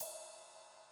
• 00's Long Ride Single Hit E Key 01.wav
Royality free ride tuned to the E note. Loudest frequency: 6660Hz
00s-long-ride-single-hit-e-key-01-biV.wav